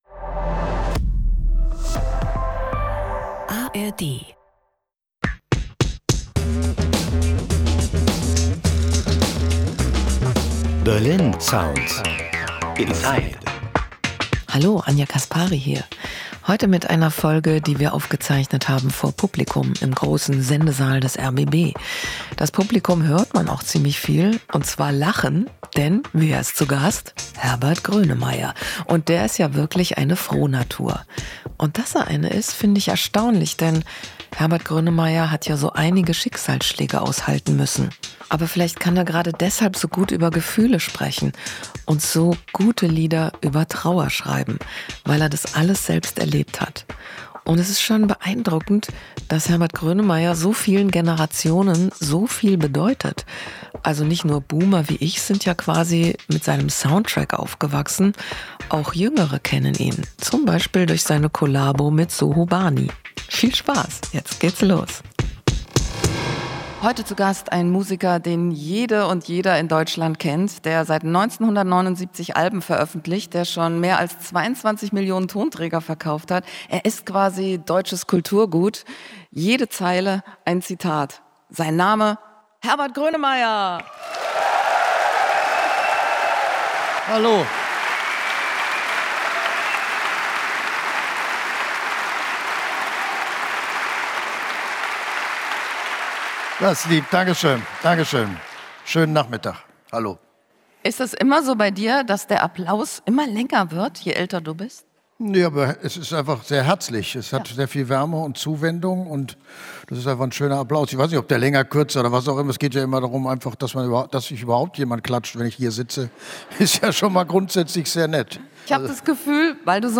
Beschreibung vor 3 Monaten In diesem Podcast, der vor Publikum im rbb Sendesaal aufgezeichnet wurde, nimmt Deutschlands musikalischer Nationalheld kein Blatt vor den Mund.